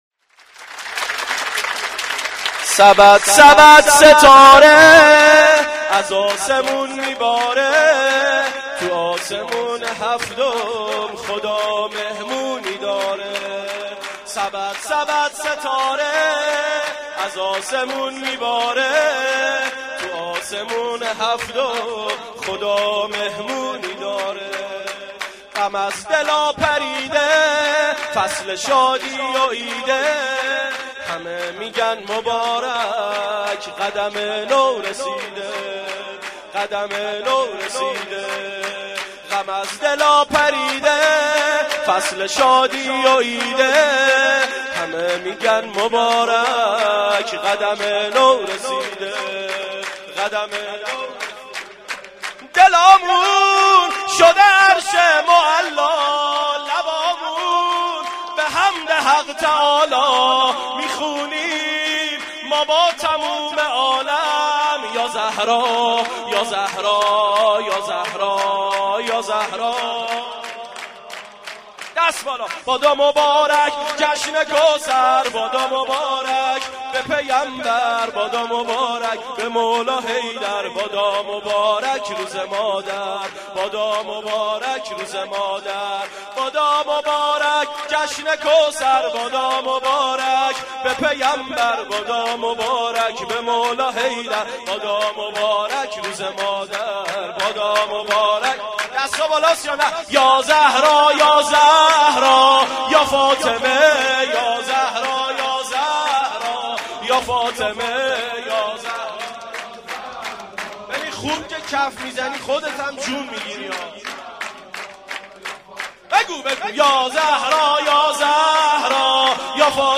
سرود/ولادت حضرت فاطمه (س) 1397